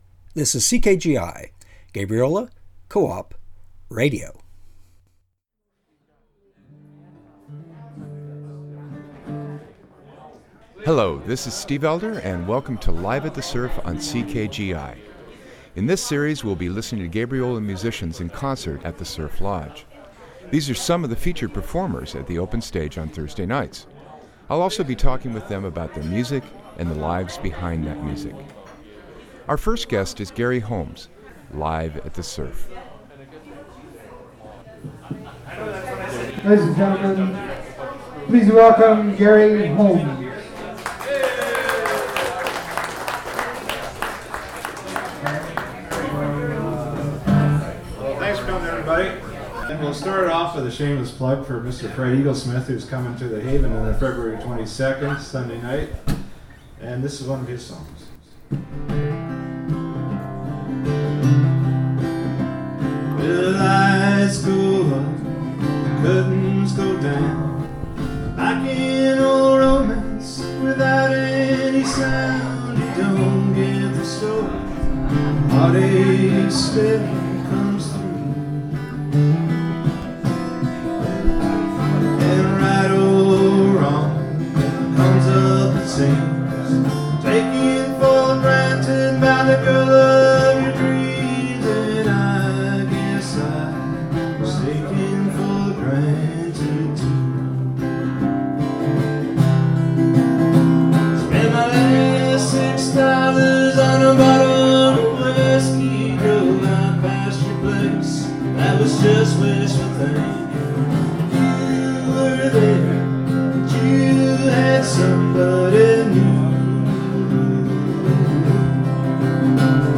Live at the Surf
Gabriola Singer
in performance at the Surf Lodge on Gabriola Island, BC